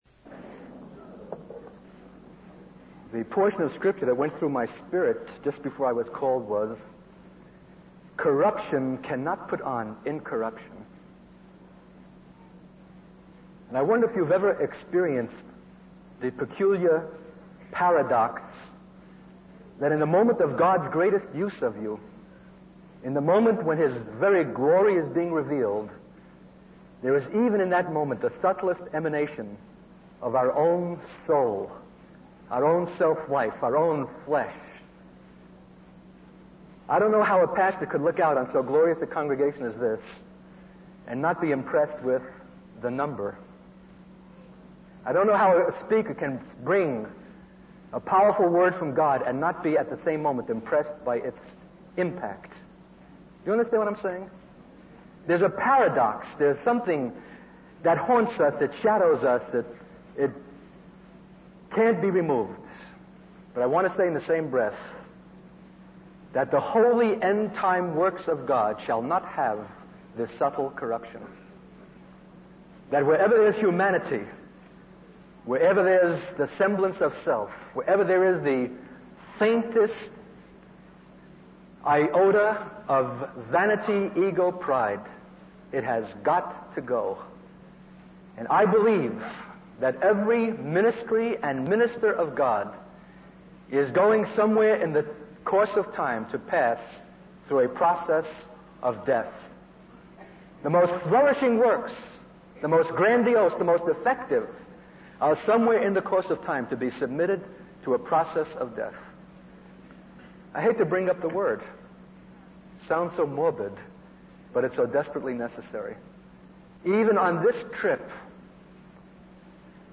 In this sermon, the speaker reflects on a gathering where many people were going to Afghanistan for missionary work after three months of court and discipleship. The speaker emphasizes the importance of waiting for the glory of God rather than relying on human efforts.